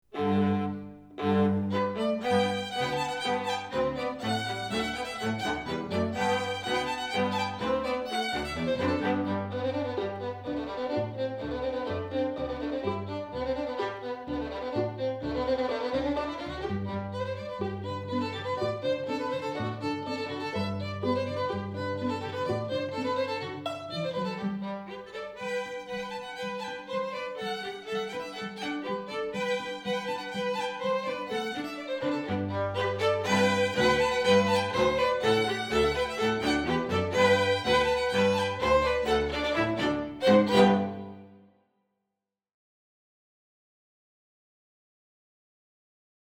Quatuor
Le Reel de la mariée[0:46 min](Musique traditionnelle - Extrait)